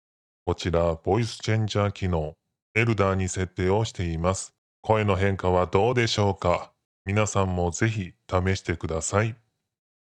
ボイスチェンジャー
ボタンひとつで声質を変化させてくれます。
■ Elder（長老）